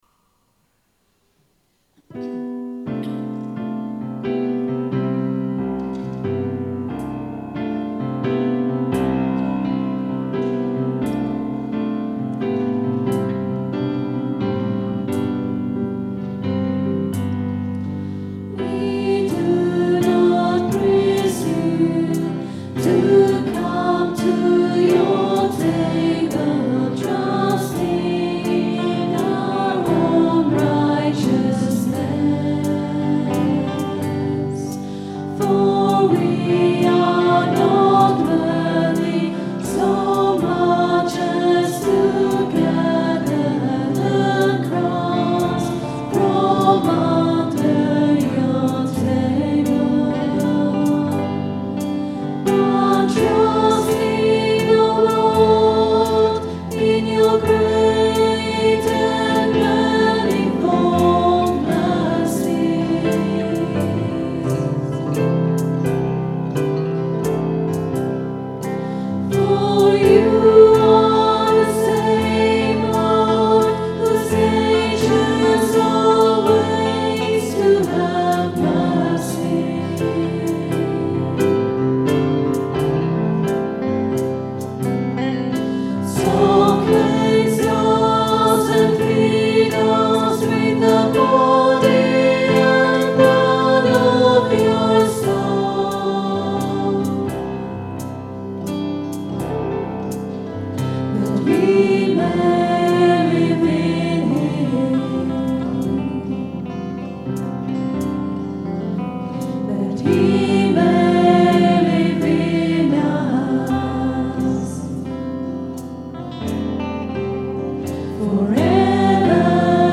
A gentle swing feel to this reverential song. Recorded at 10am Mass on Sunday 13th July 2008. Recorded on the Zoom H4 digital stereo recorder through a Behringer SL2442FX mixer.